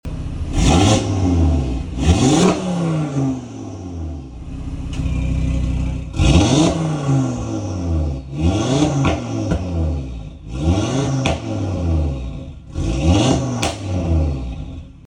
Listen to this 5-Cylinder brute
• RS Sports Exhaust System with Black Oval Tips
• Wastegate Rattle Patch with 25% increase in Overrun Exhaust Crackle over Factory
audi-rs3-daytona-grey-sportback-mrc-stage-2-tuned-modified-dxe.mp3